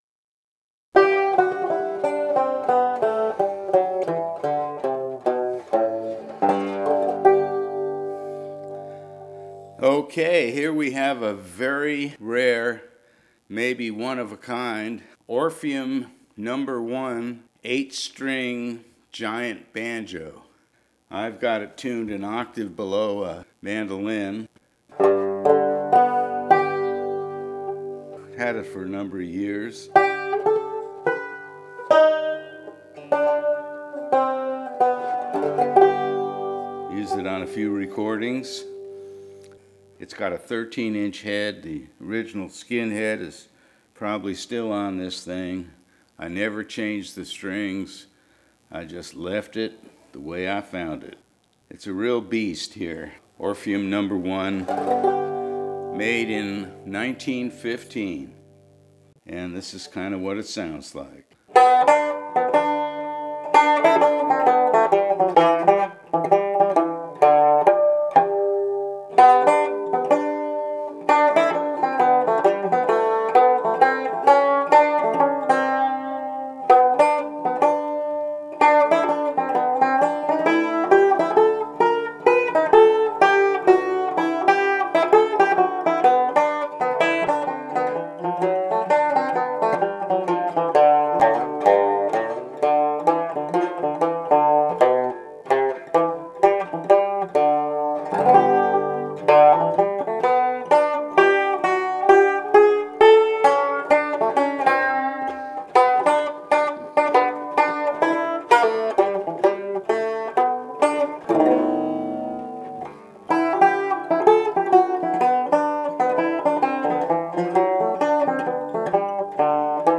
1915 Orpheum No. 1 Octave Mandolin-Banjo" sample